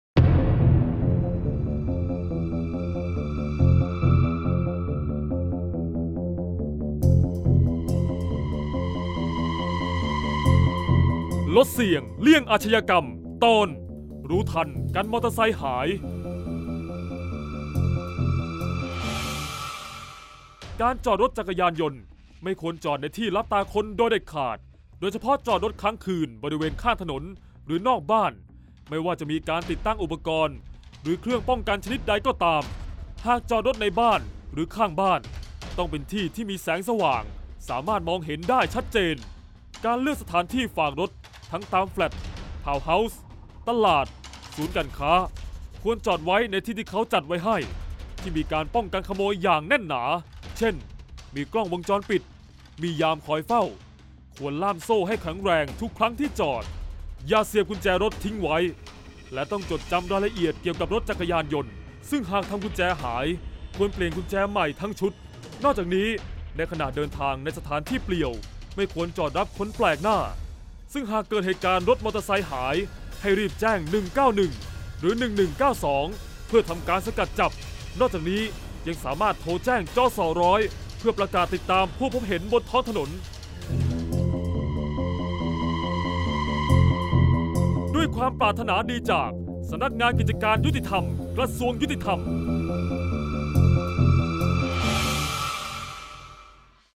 เสียงบรรยาย ลดเสี่ยงเลี่ยงอาชญากรรม 48-รู้ทันกันมอเตอร์ไซด์หาย